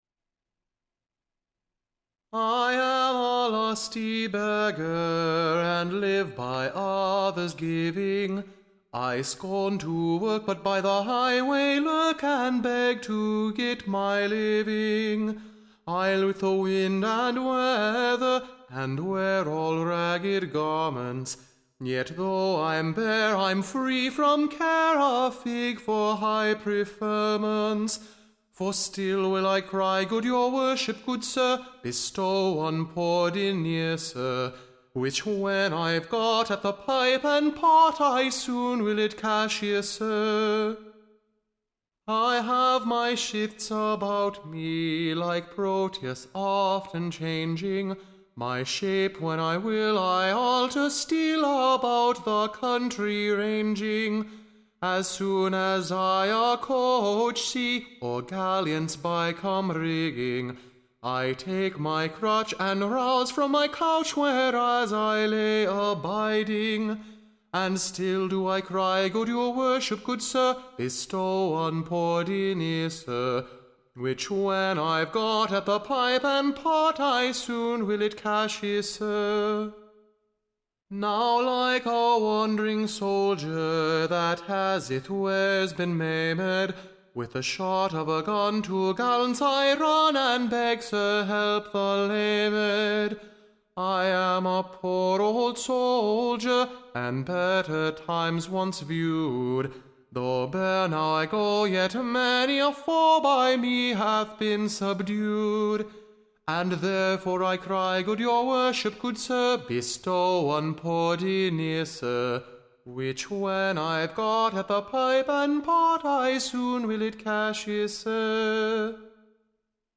Recording Information Ballad Title The cunning Northerne Begger / VVho all the By-standers doth earnestly pray, / To bestow a penny upon him today. Tune Imprint To the tune of Tom of Bedlam.